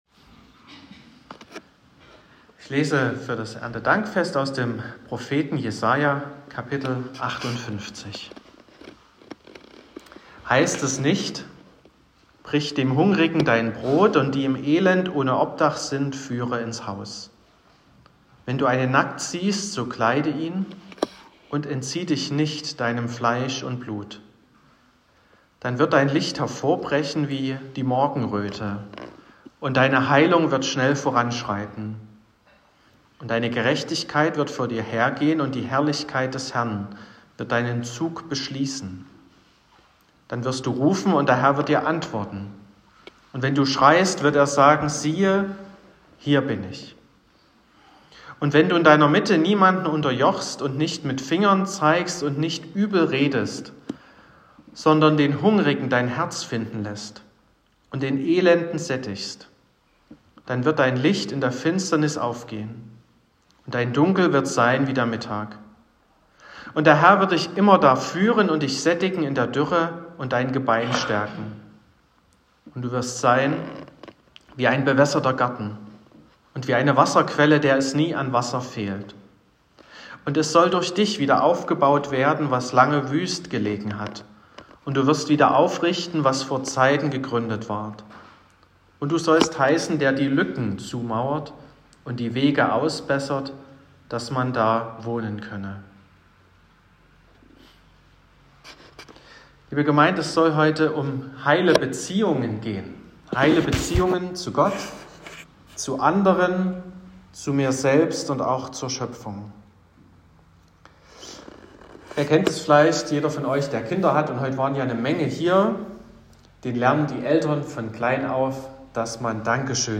05.10.2025 – Gottesdienst zum Erntedankfest
Predigt und Aufzeichnungen